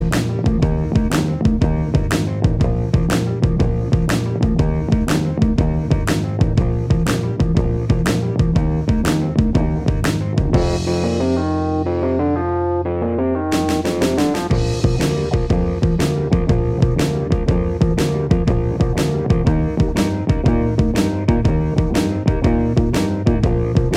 Minus Lead Guitar Pop (1960s) 3:25 Buy £1.50